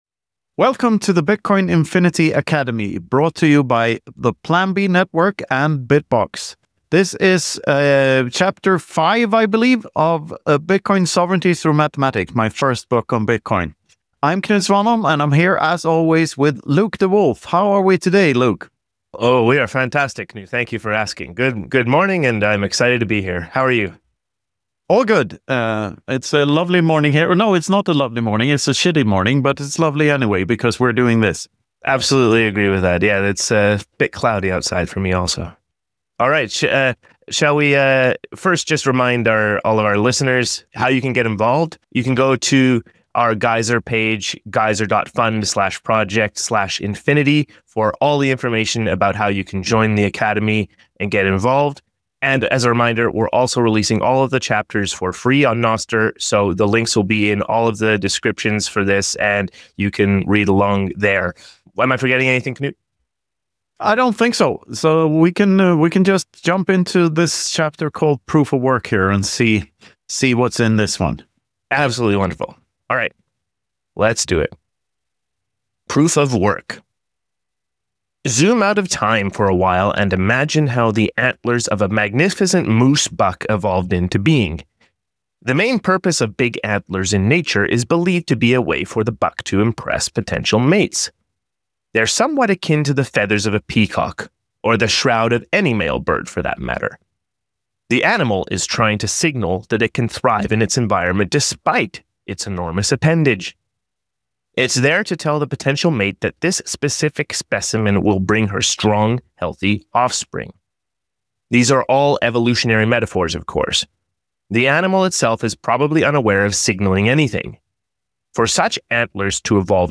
The hosts also explore some thought experiments, including Bitcoin's role in a simulated reality.